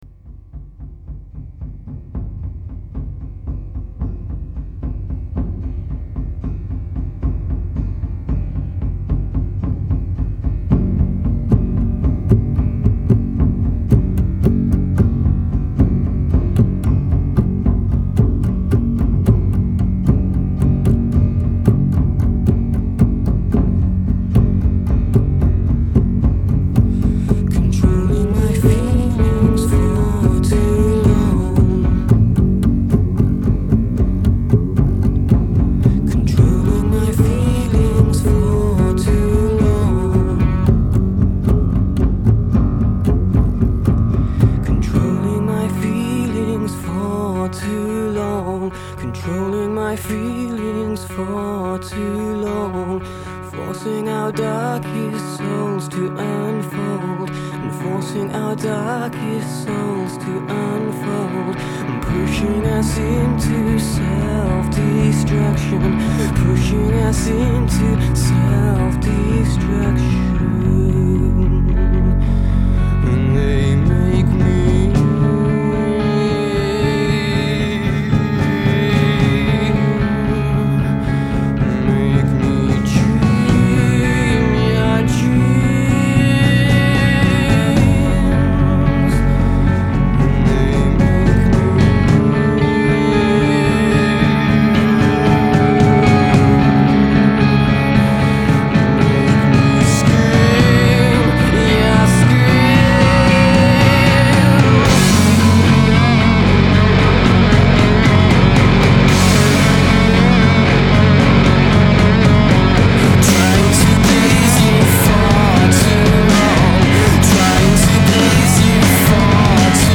Рок Альтернативный рок